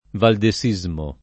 valdesismo [ valde S&@ mo ]